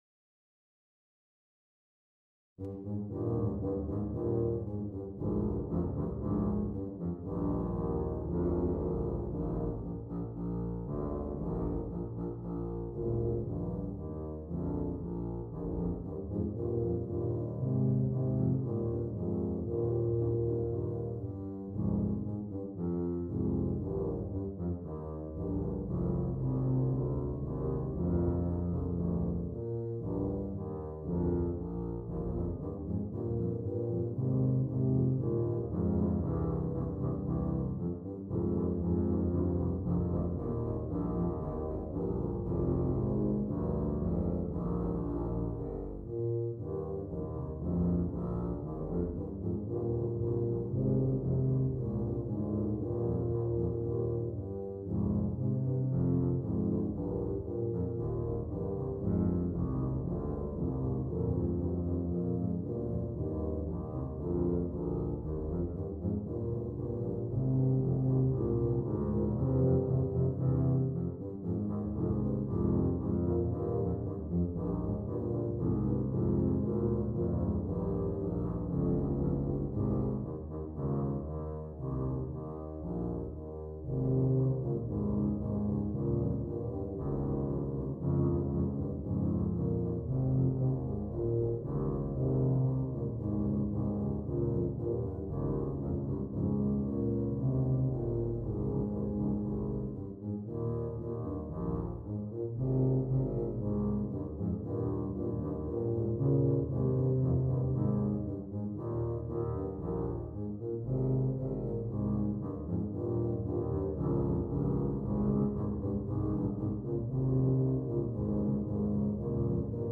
3 Tubas